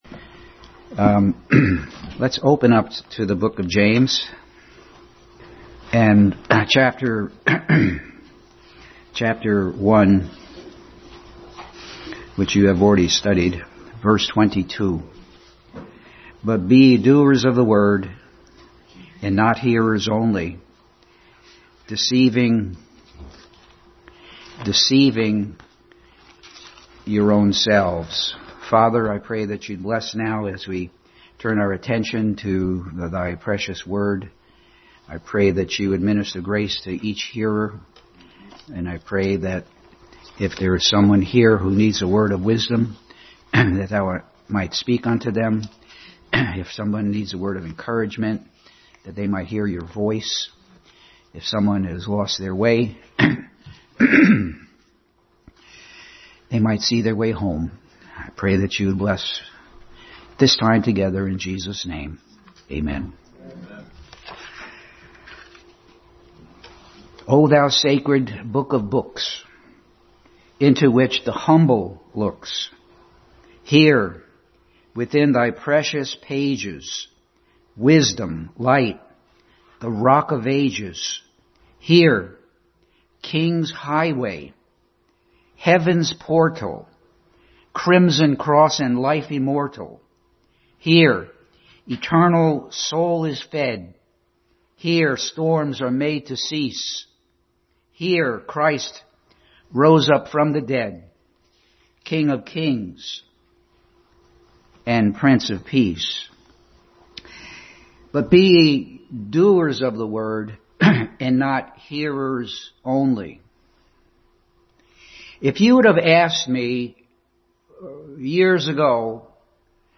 Passage: James 1:22, Hebrews 11:1 Service Type: Sunday School